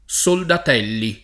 [ S oldat $ lli ]